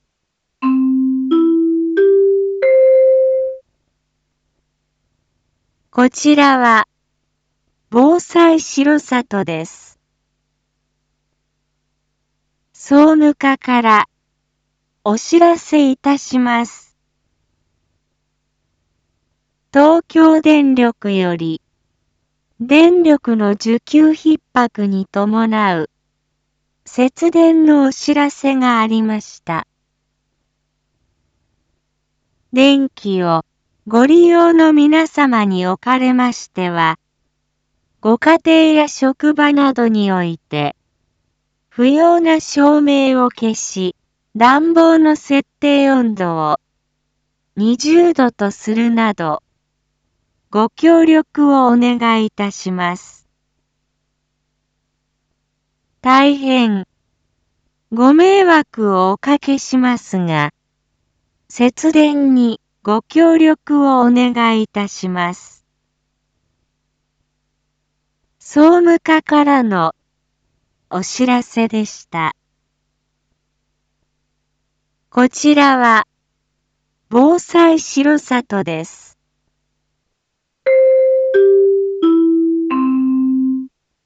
Back Home 一般放送情報 音声放送 再生 一般放送情報 登録日時：2022-03-22 11:51:26 タイトル：R4.3.22 11:50 放送分 インフォメーション：こちらは、防災しろさとです。